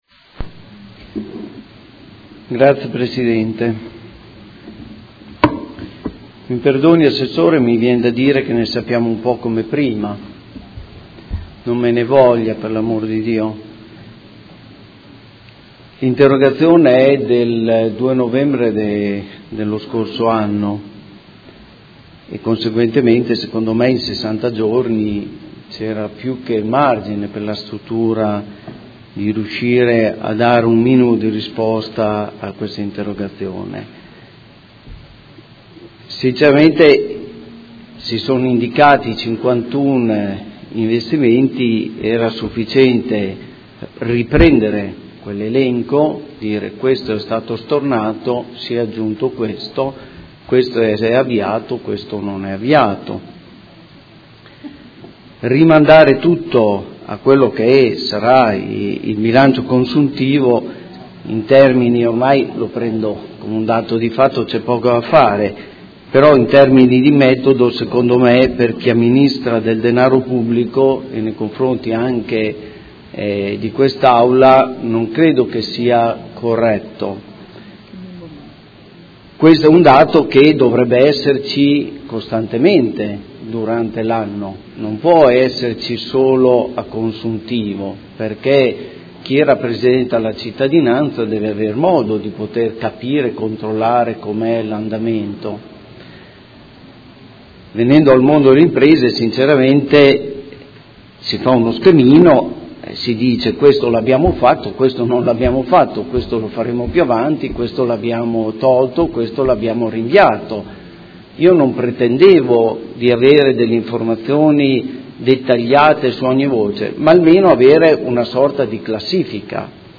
Seduta del 14/01/2016. Conclude interrogazione del Consigliere Montanini (CambiAMOdena) avente per oggetto: Situazione investimenti